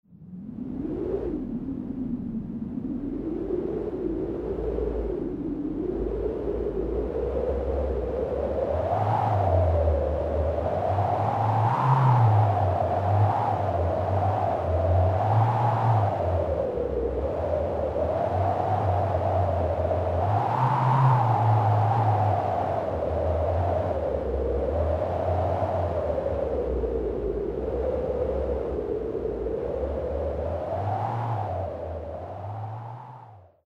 Nagranie 1 - wiatr halny - Scenariusz 27.mp3